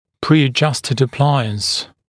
[prɪə’ʤʌstəd ə’plaɪəns][приэ’джастэд э’плайэнс]аппаратура (аппарат) с заданными характеристиками (например, брекет-система с заложенными значениями всех основных параметров, которые, как правило, определяются прописью)